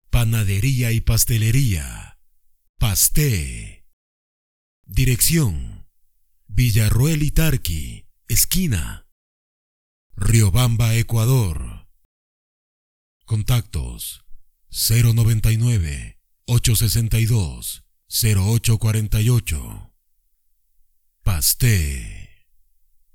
Barítono bajo, con acento neutro, que puede alcanzar figuras detalladas en el romance o en la narración.
Sprechprobe: Industrie (Muttersprache):